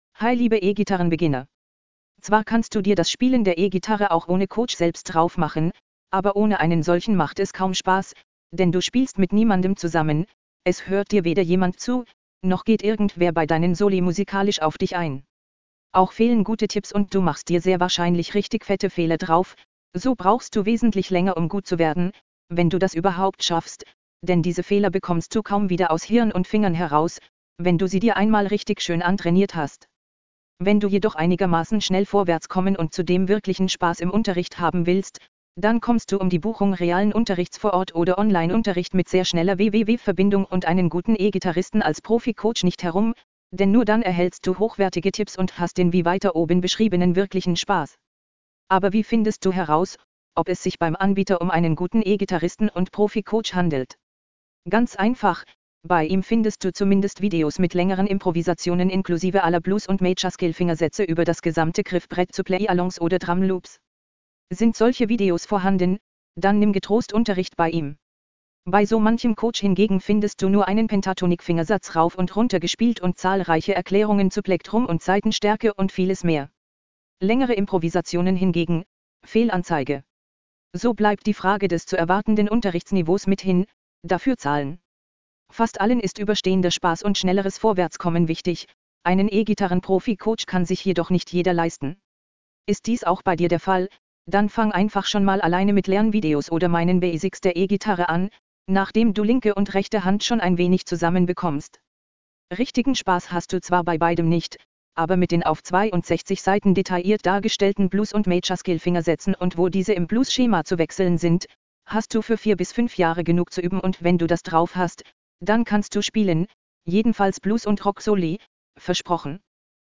Audio  (txt 2 speach => "Balabolka")